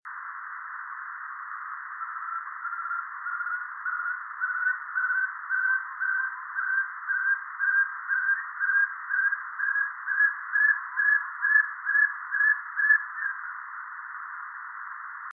61-2扇平2012深山竹雞1.WAV
臺灣山鷓鴣 Arborophila crudigularis
高雄市 茂林區 扇平
錄音環境 森林
行為描述 鳴叫